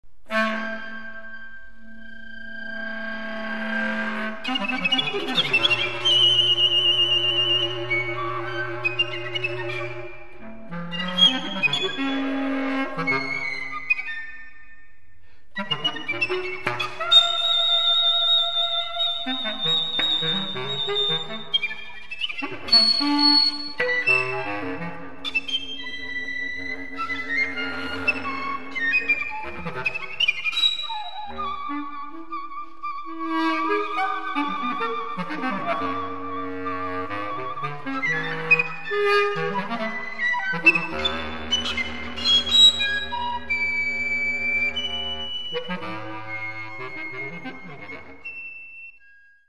for flute and clarinet